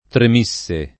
tremisse